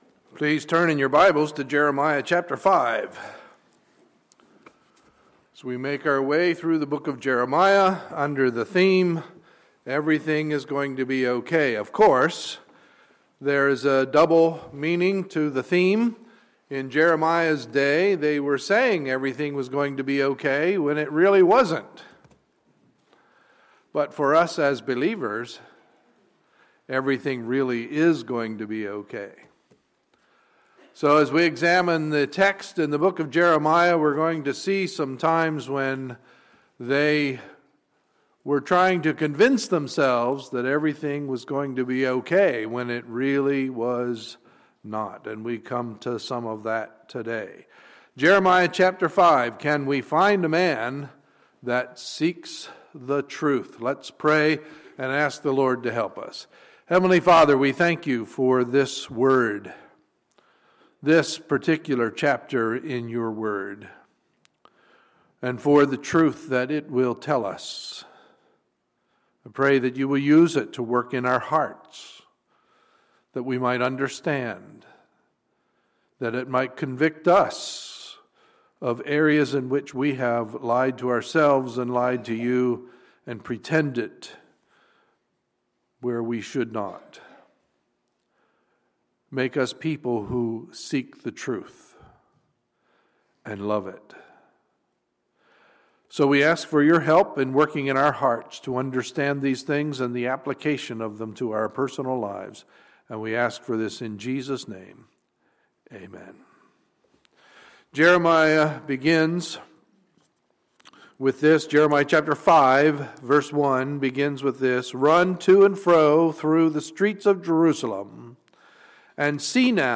Sunday, February 08, 2015 – Sunday Morning Service